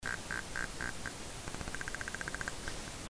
iPhone 17 Pro Camera Making A Clicking Sound
Is this what the front camera noise sounds like for others when FaceID is started but doesn't actually unlock?
(I've boosted it by +36dB, incidentally – it really is very quiet IRL.) Attachments FaceID noise.mp3 FaceID noise.mp3 56.1 KB